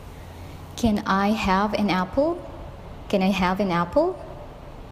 Can⌒I                    canai
as soon⌒as           as-soonas
an⌒apple              anapple